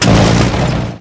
tank-engine-load-reverse-4.ogg